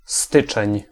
Ääntäminen
Ääntäminen France: IPA: [ɑ̃ ʒɑ̃.vje] Tuntematon aksentti: IPA: /ʒɑ̃.vje/ IPA: /ʒɑ̃ˈvjeː/ Haettu sana löytyi näillä lähdekielillä: ranska Käännös Ääninäyte Substantiivit 1. styczeń {m} Suku: m .